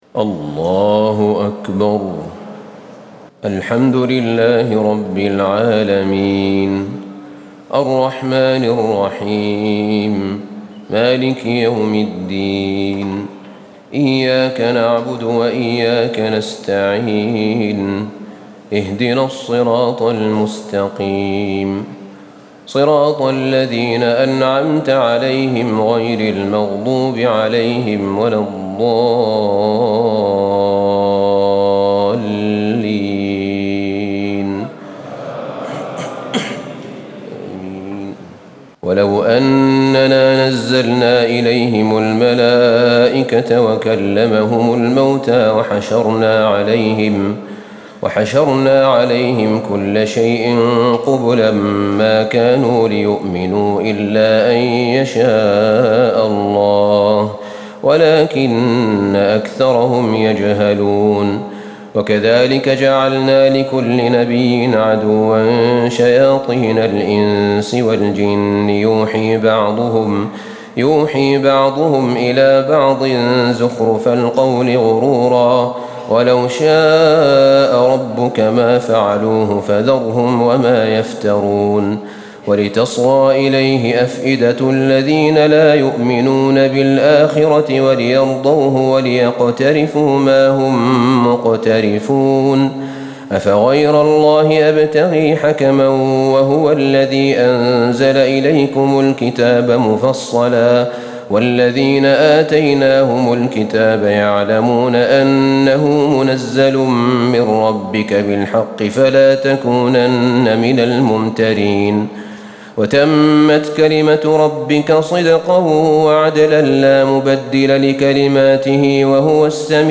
تهجد ليلة 28 رمضان 1437هـ من سورتي الأنعام (111-165) و الأعراف (1-30) Tahajjud 28 st night Ramadan 1437H from Surah Al-An’aam and Al-A’raf > تراويح الحرم النبوي عام 1437 🕌 > التراويح - تلاوات الحرمين